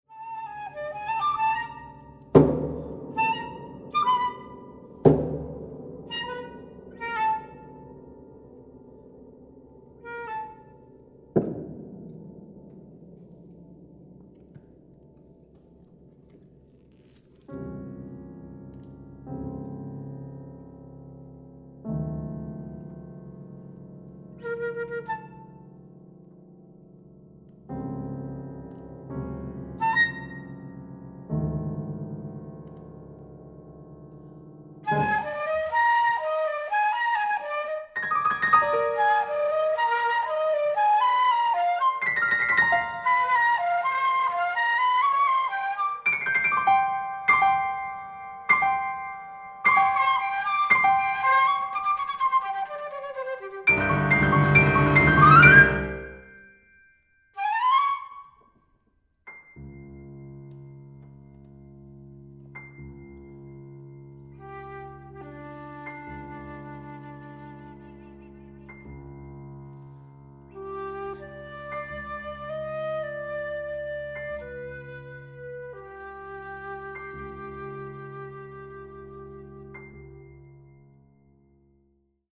Works for piano, flute, and cello.
Recital Hall at the University of Tasmania
Australian, Classical